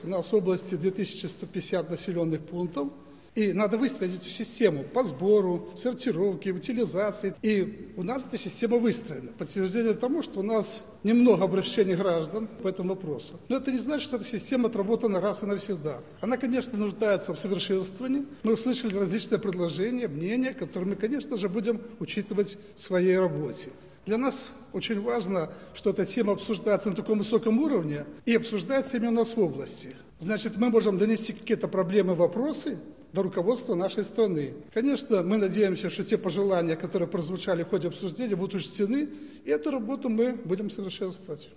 Местные органы власти, депутаты всех уровней отвечают за организацию работы с ТКО, такое мнение выразил член Совета Республики, председатель Брестского областного Совета депутатов Юрий Наркевич во время выездного заседания экспертно-консультативного совета при Президиуме Совета Республики Национального собрания.
narkevich-sinhron.ogg